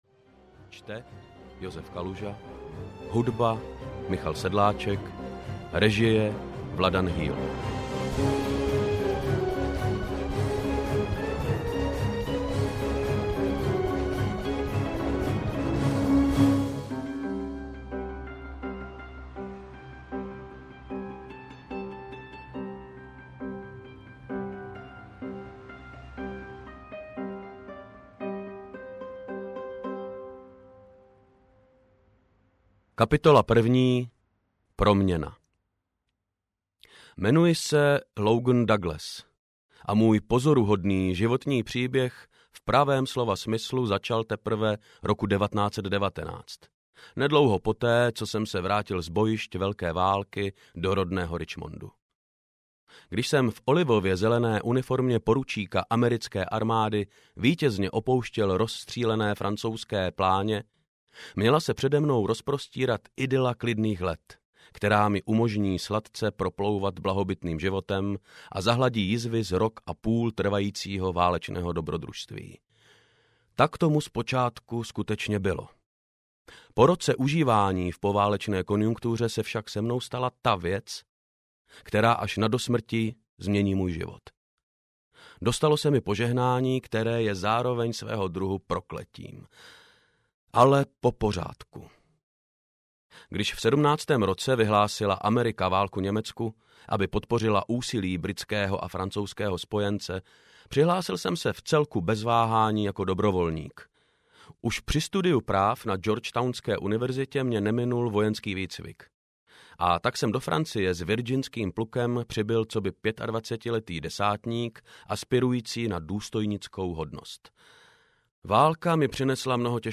Výpravy jinam audiokniha
Ukázka z knihy